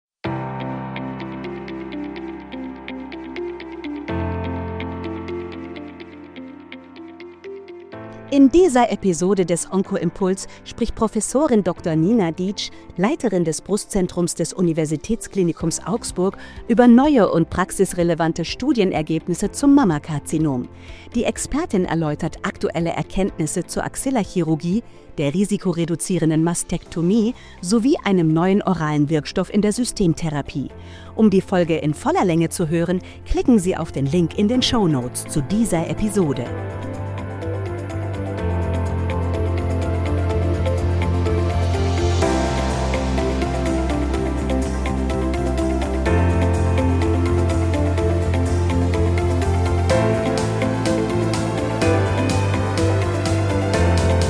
Interview zum San Antonio Breast Cancer Symposium 2024 mit Prof.